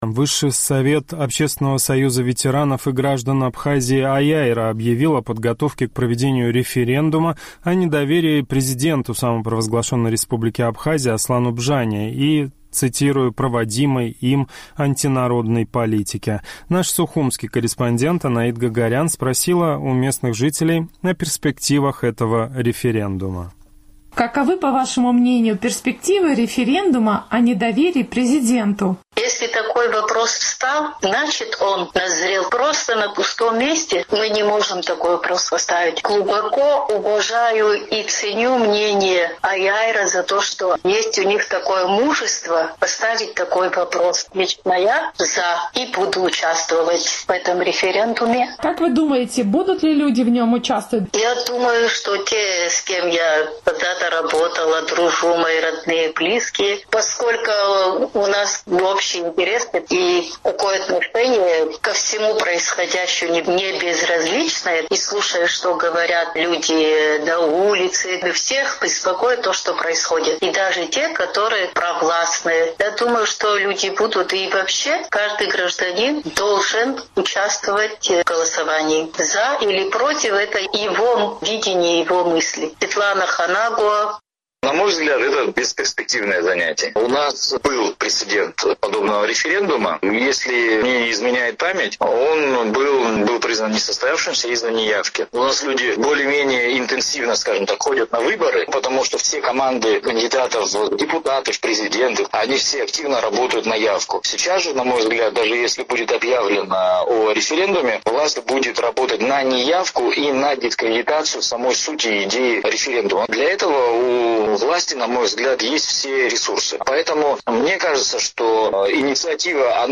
Общественный Союз ветеранов и граждан Абхазии «Аиааира» объявил о подготовке к проведению референдума о недоверии Аслану Бжания и «проводимой им антинародной политики». «Эхо Кавказа» поинтересовалось у местных жителей, каковы его перспективы?